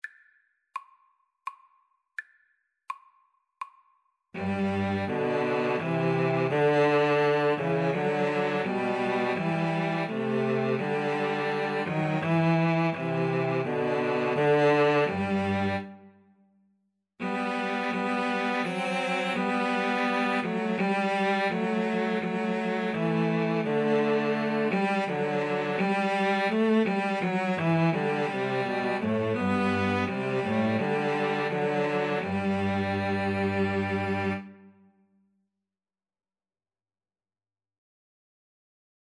3/4 (View more 3/4 Music)
Cello Trio  (View more Easy Cello Trio Music)